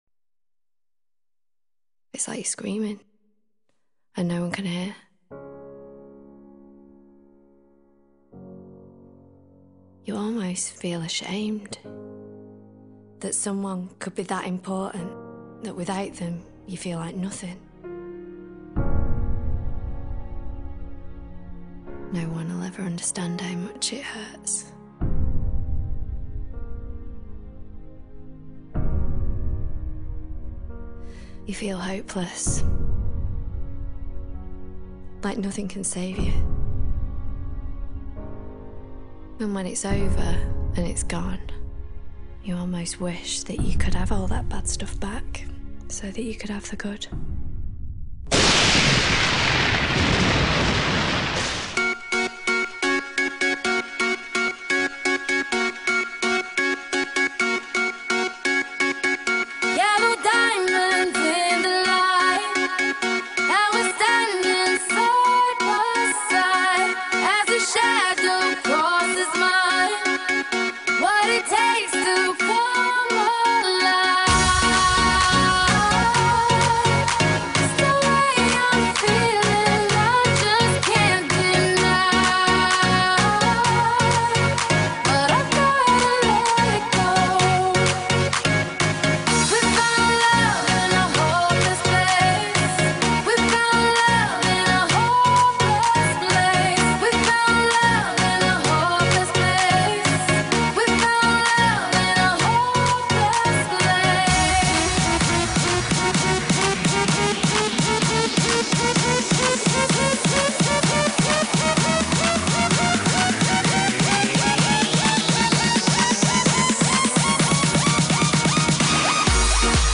Pop, Dance, House, Electro